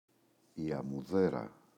αμμουδέρα, η [amuꞋðera]